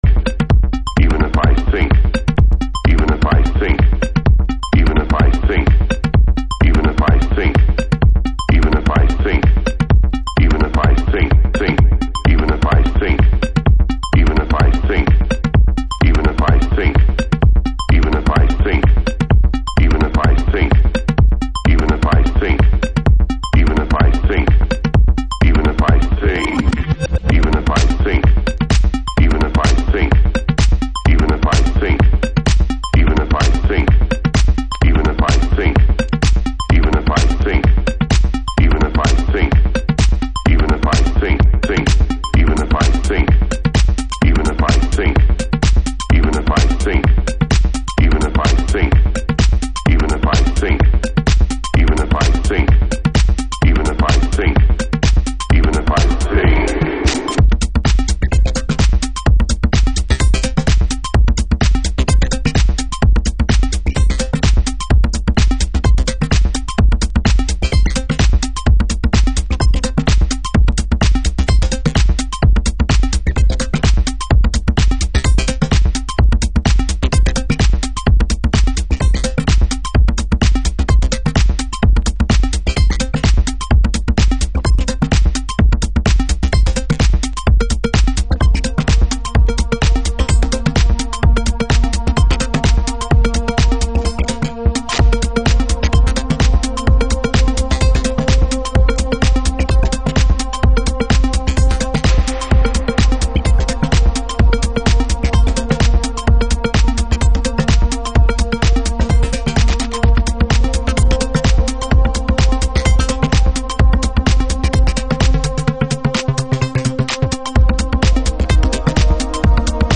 House / Techno
シカゴハウスのミニマルな要素を抽出したメロディックテクノ。